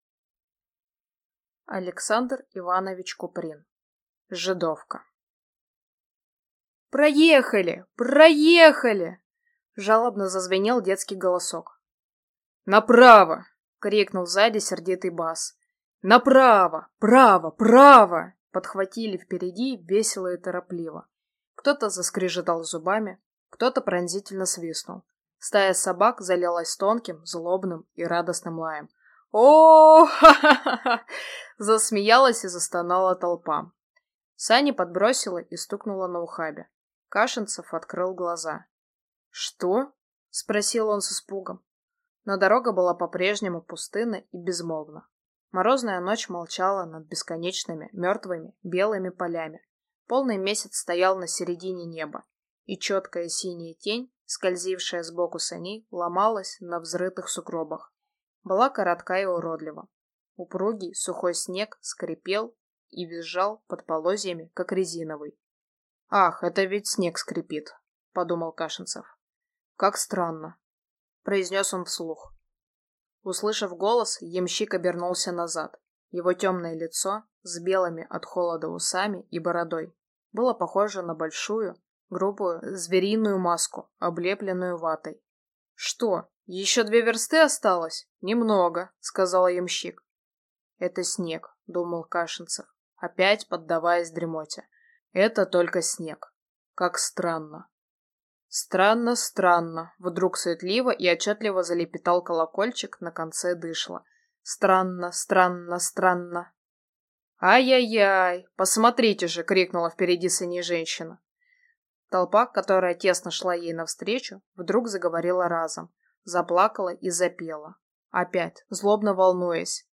Аудиокнига Жидовка | Библиотека аудиокниг
Читает аудиокнигу